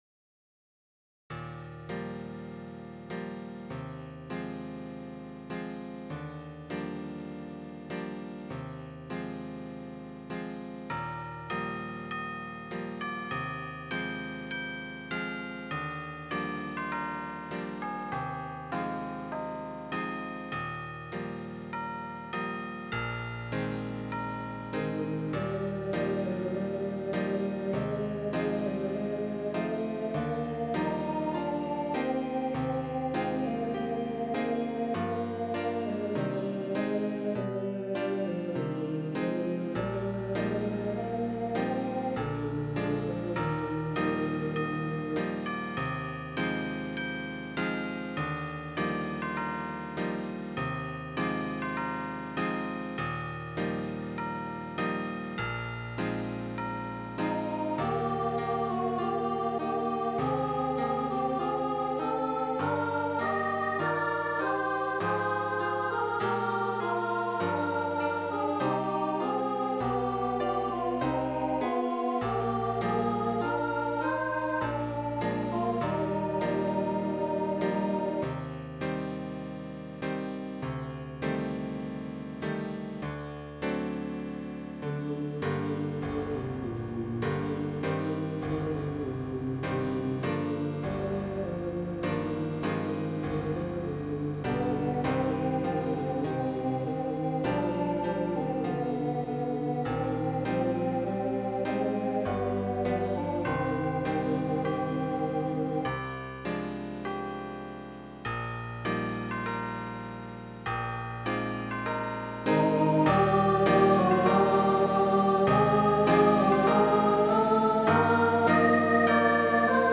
easy SAB with bells